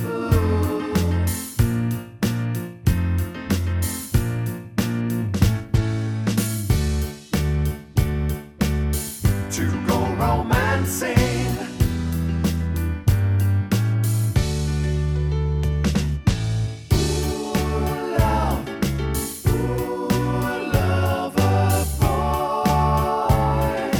Down One Semitone Rock 3:02 Buy £1.50